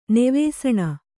♪ nevēsaṇa